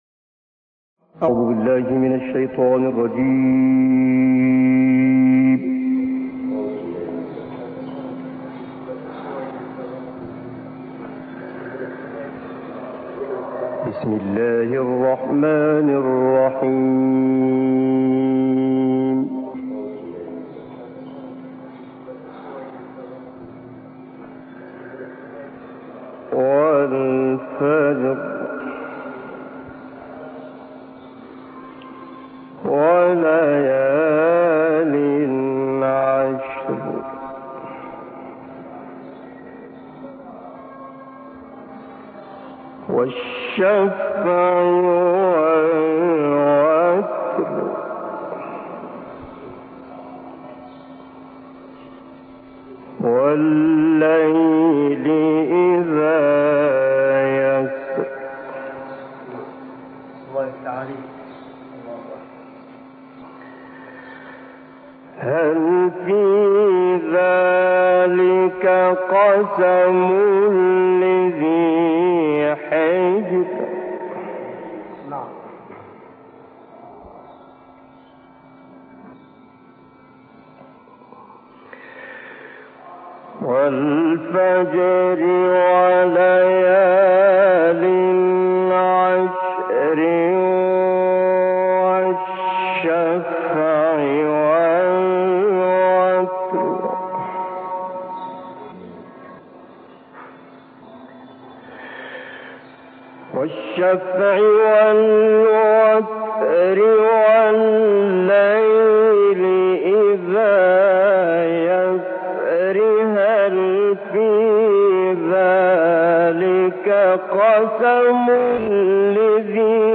صوت | کرسی تلاوت مجازی سوره فجر